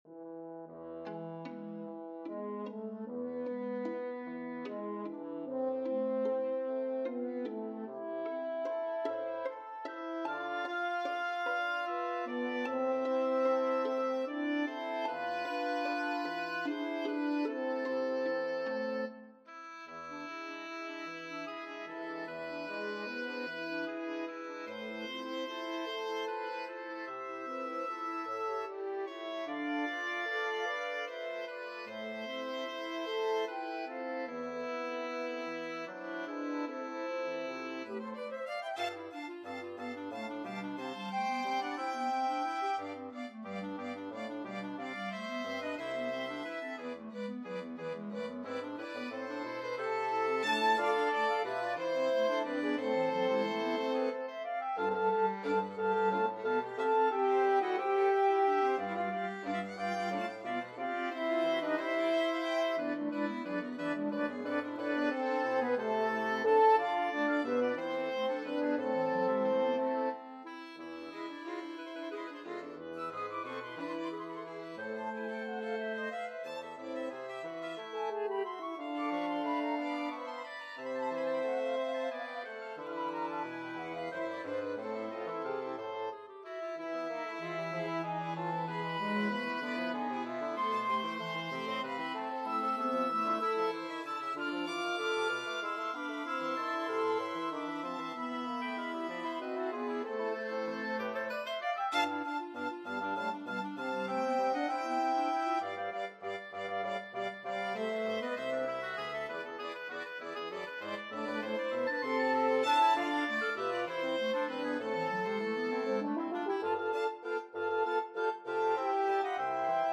その優雅な景色が目に浮かぶような流れる旋律が美しい名曲です。
そして３拍子は一般的にワルツ（円舞曲）と呼ばれることが多くれ、リズムに乗りやすいダンス調が印象的です。
・華やかなオーケストラ曲でもある優雅な曲調
・リズムに乗りやすい３拍子
・基本は＃♭のないハ長調